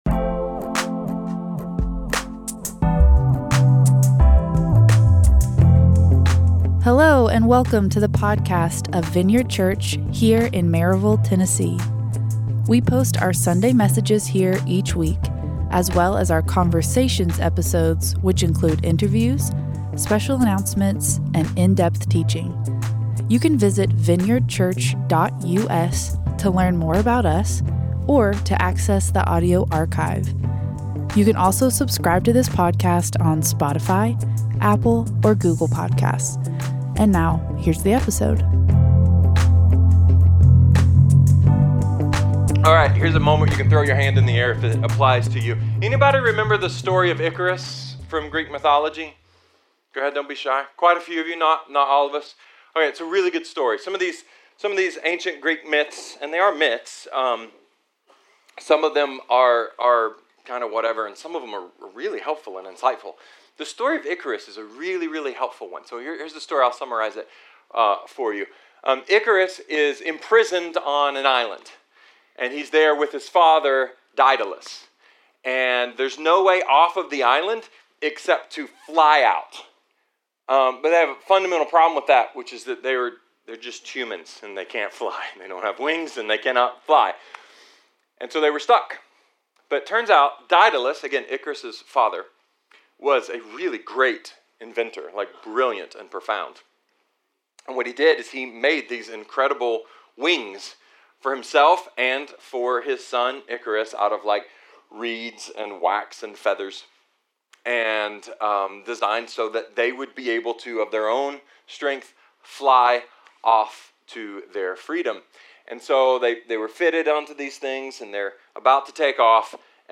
A message from the series "Empowered."